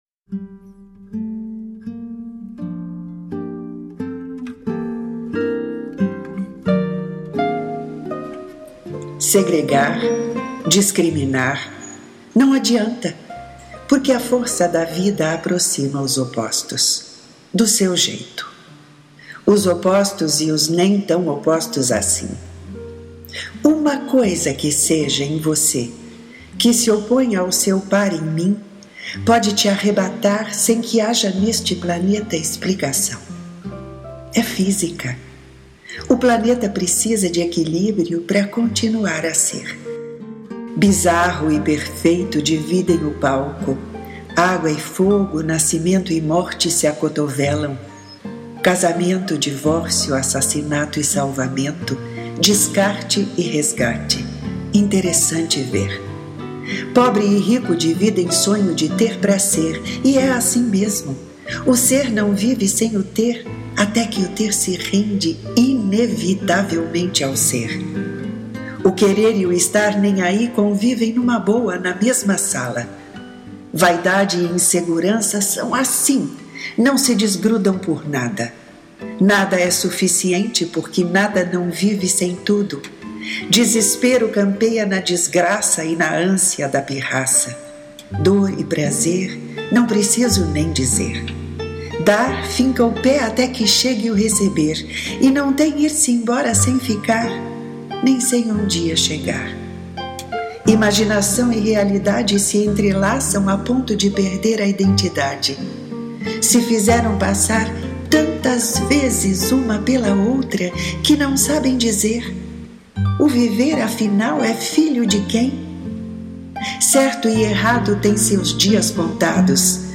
Ouça “De pares” na voz e sonorizado pela autora